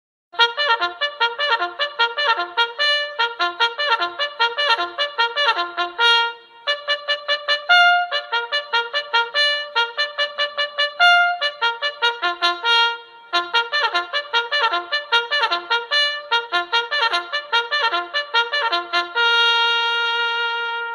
Sonido+De+Trompeta+Para+Despertar +Sonido+De+Trompeta+De+Campamento (audio/mpeg)
TROMPETA familia: viento metal